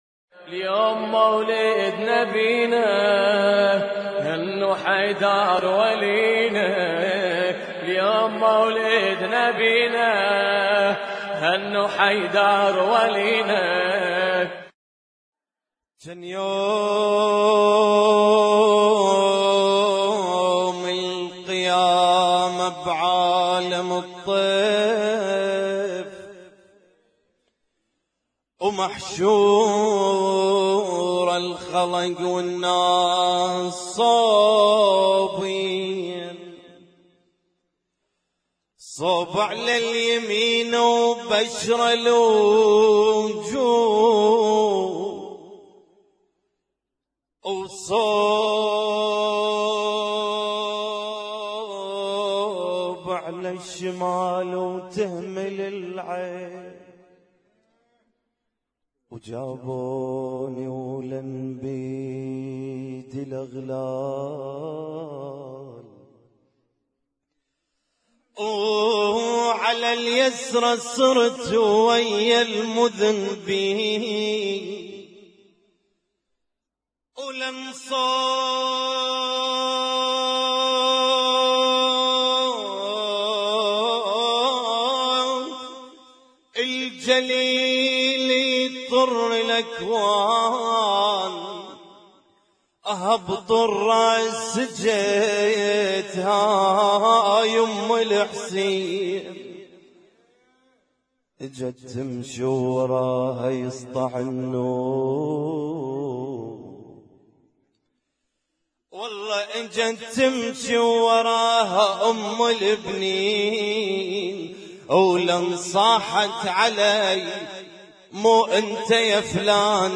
Husainyt Alnoor Rumaithiya Kuwait
اسم التصنيف: المـكتبة الصــوتيه >> المواليد >> المواليد 1437